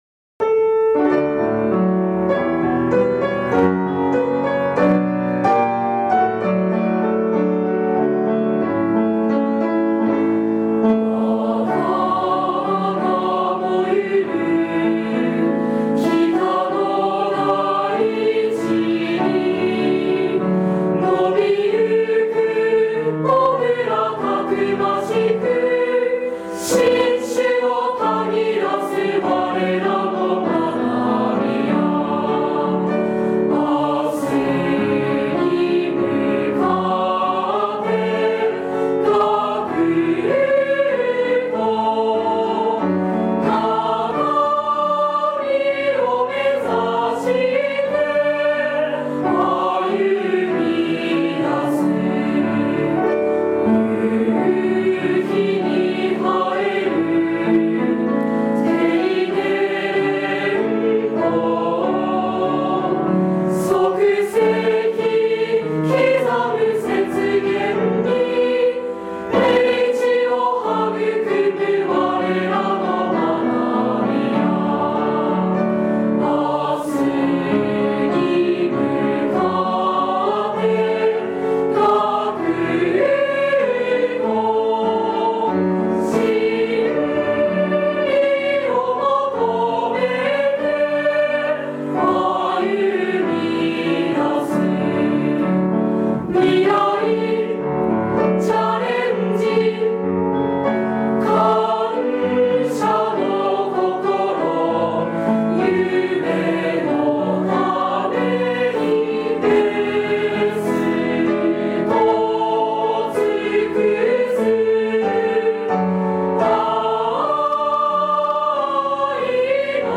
札幌あいの里　校歌_01.mp3